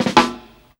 130JAMROLL-R.wav